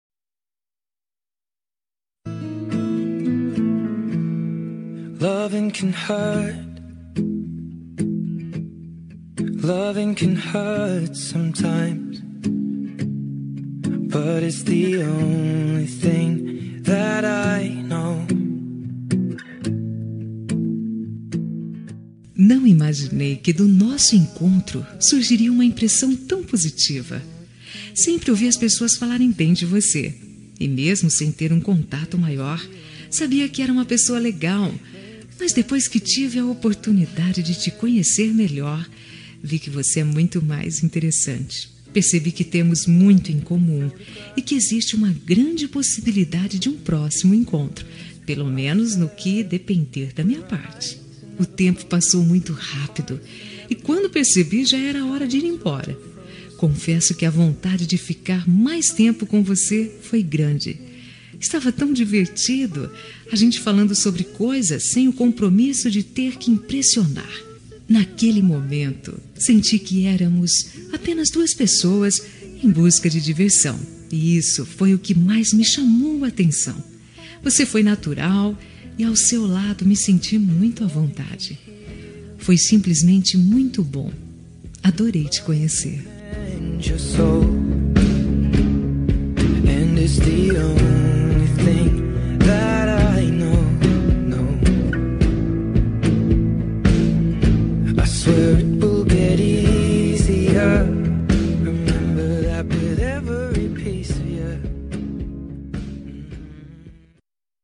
Telemensagem Momentos Especiais – Voz Feminina – Cód: 4108 – Adorei te Conhecer
4108-adorei-te-conhecer-fem.m4a